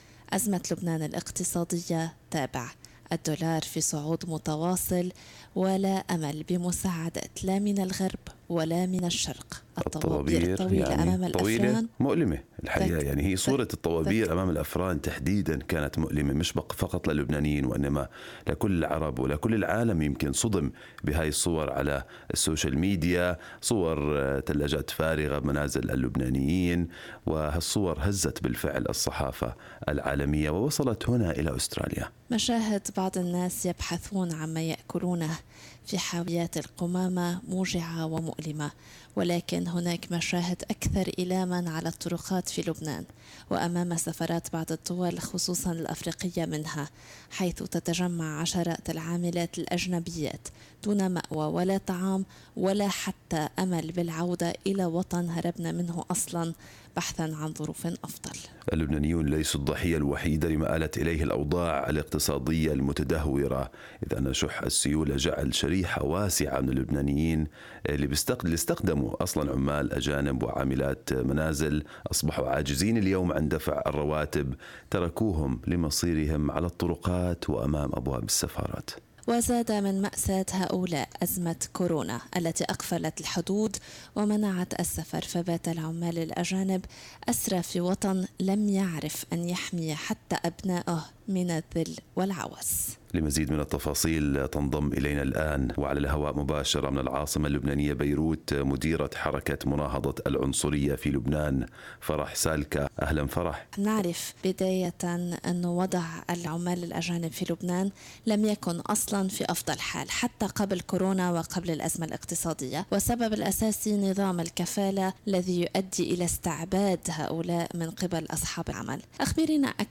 "أشبه بالجحيم": عاملة أجنبية تصف تجربة العمل في لبنان تحت نظام الكفالة